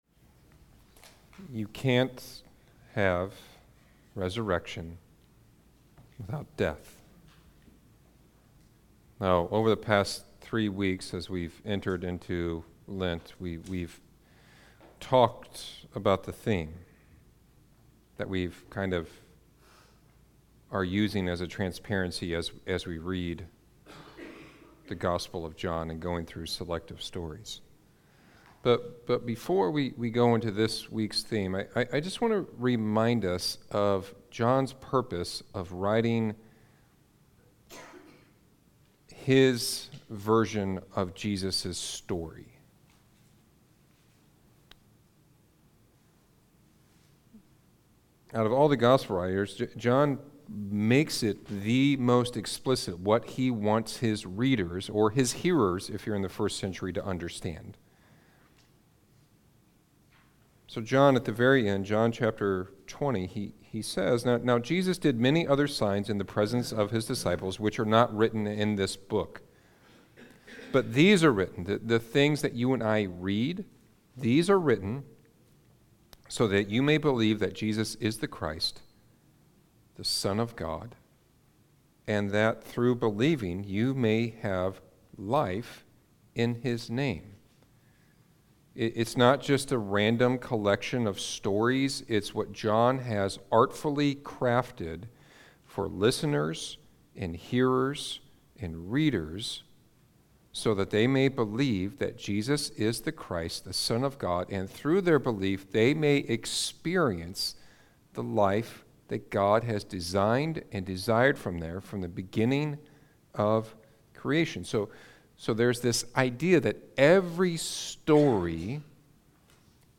Sermons | Beacon Church